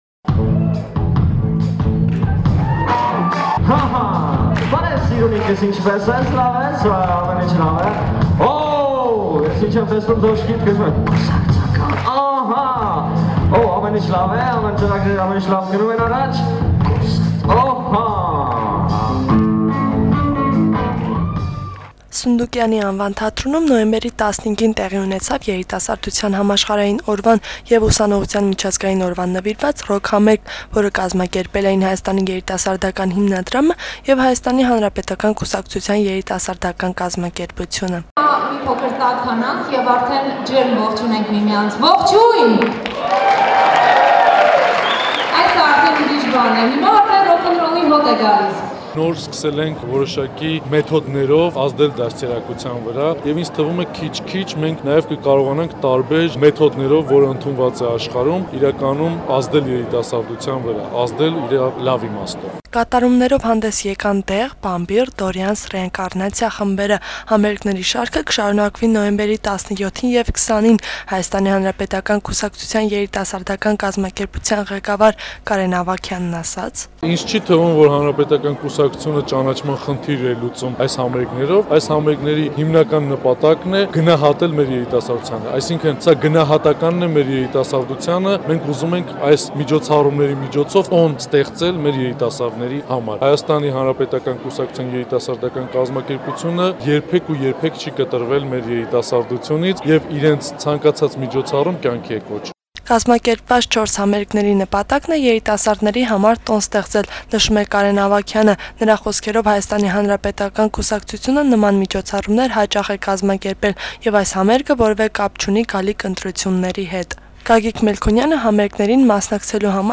Սունդուկյանի անվան թատրոնում նոյեմբերի 15-ին, տեղի ունեցավ Երիտասարդության համաշխարհային օրվան եւ Ուսանողության միջազգային օրվան նվիրված ռոք համերգ, որը կազմակերպել էին Հայաստանի երիտասարդական հիմնադրամը եւ ՀՀԿ երիտասարդական կազմակերպությունը։